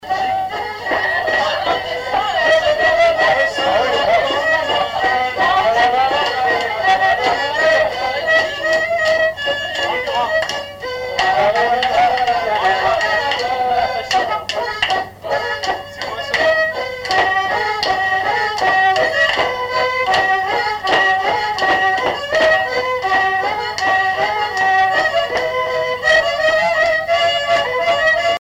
Répertoire d'un bal folk par de jeunes musiciens locaux
Pièce musicale inédite